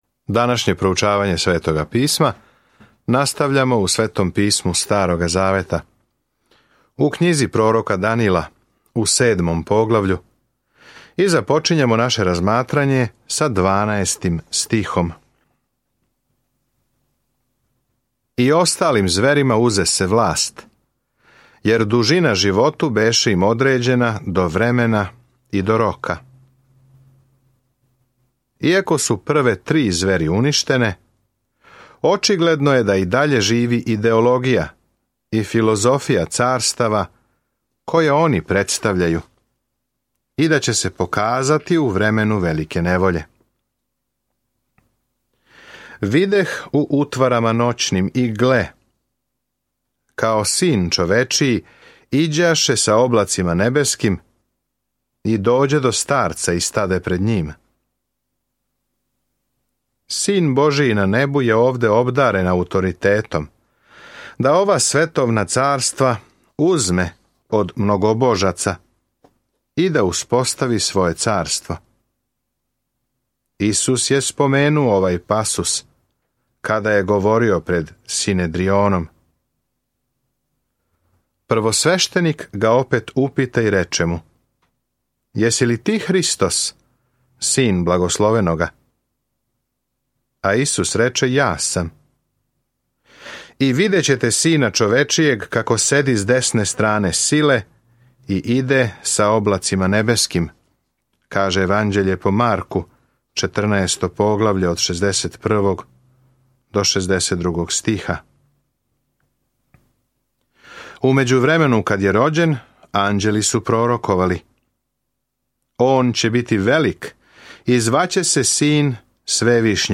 Sveto Pismo Knjiga proroka Danila 7:12-21 Dan 17 Započni ovaj plan Dan 19 O ovom planu Књига пророка Данила је и биографија човека који је веровао Богу и пророчка визија о томе ко ће на крају завладати светом. Свакодневно путујте кроз Данила док слушате аудио студију и читате одабране стихове из Божје речи.